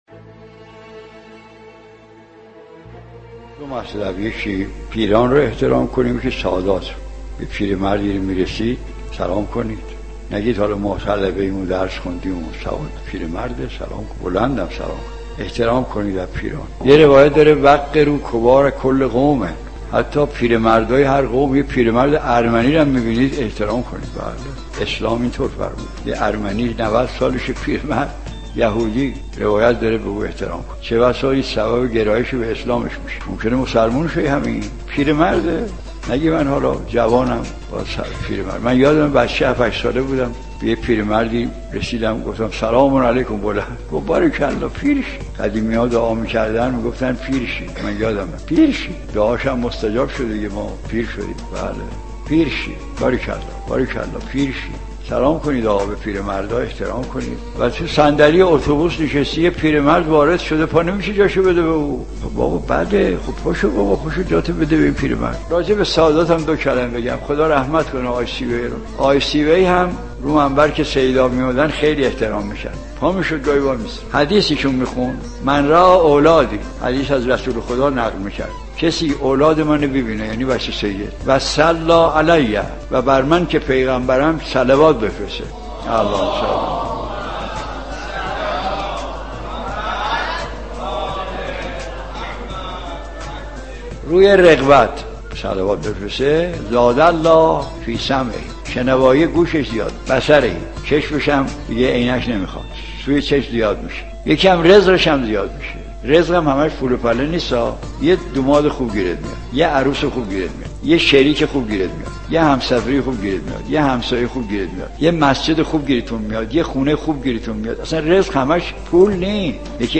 سخنرانی آیت الله مجتهدی تهرانی ره